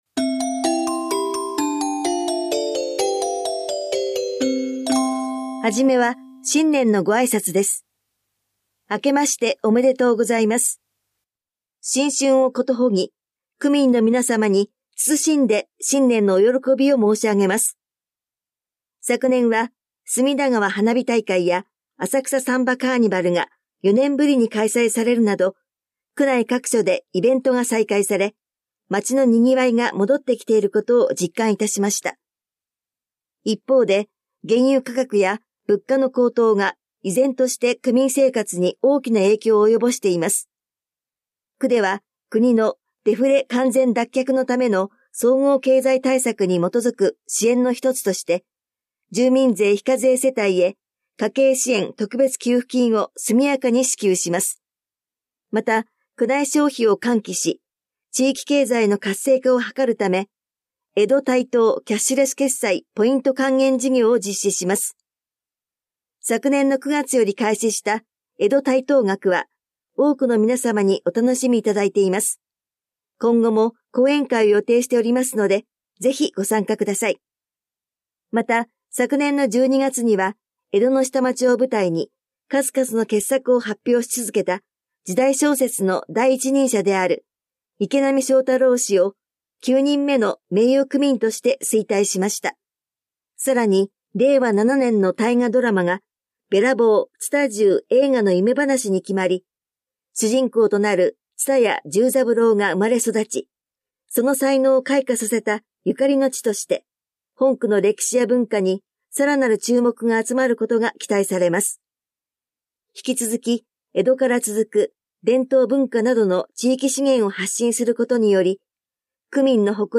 広報「たいとう」令和6年1月1日号の音声読み上げデータです。